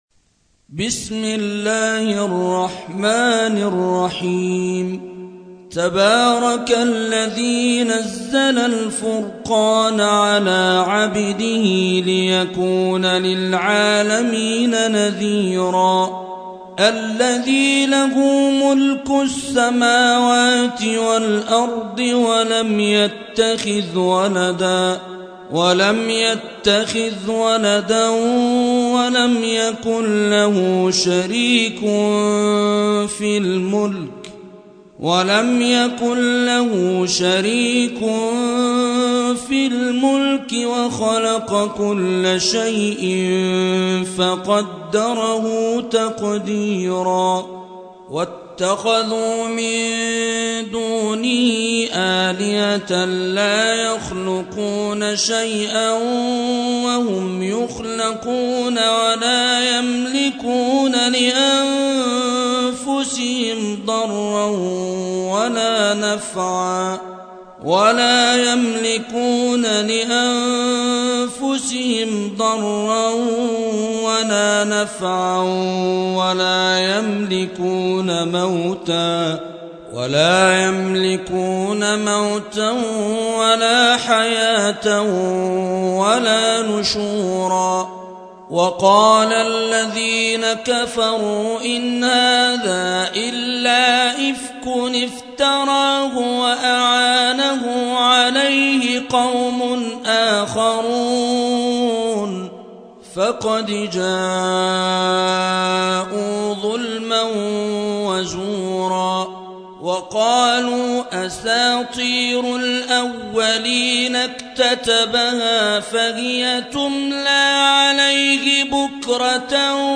25. سورة الفرقان / القارئ